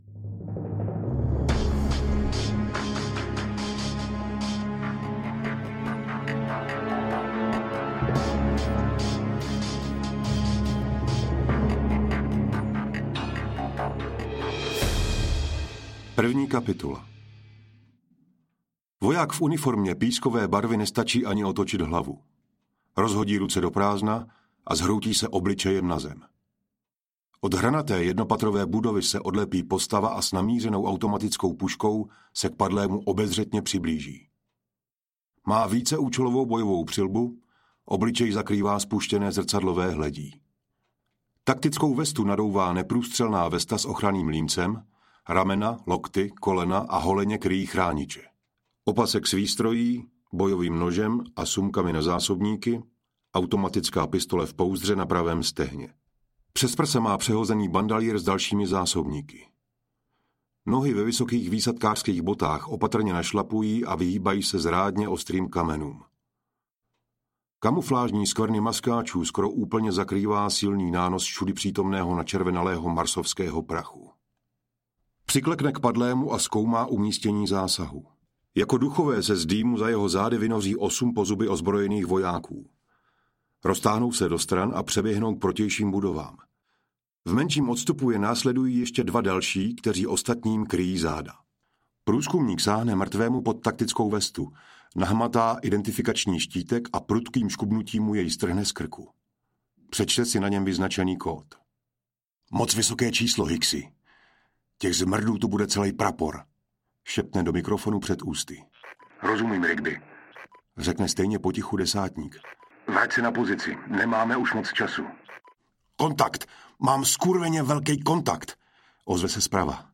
Mariňáci 1 audiokniha
Ukázka z knihy